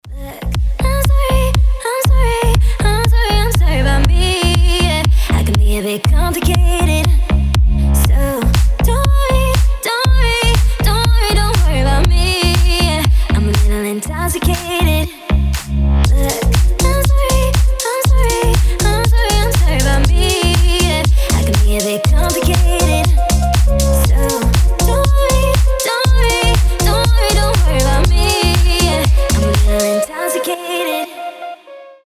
красивый женский голос
house